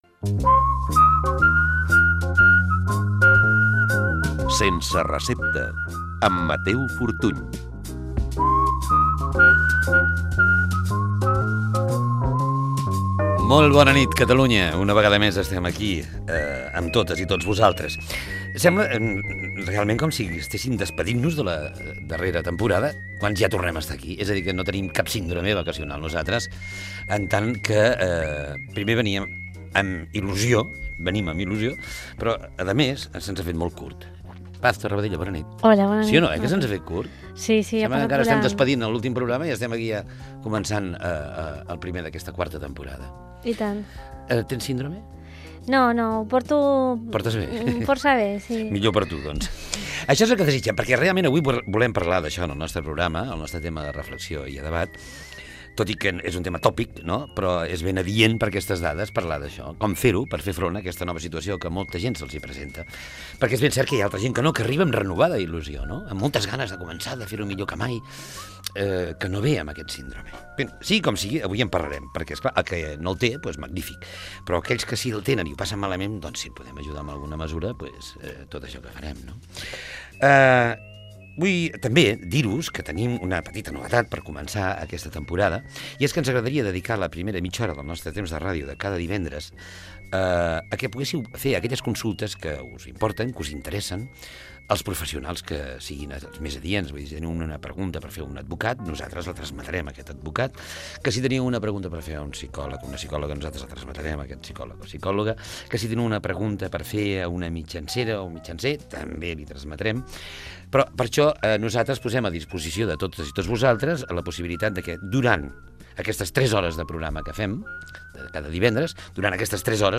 Indicatiu del programa, presentació inicial, proposta de debat del programa (síndrome postvacacional), comentaris sobre un atemptat terrorista i sumari.
Entreteniment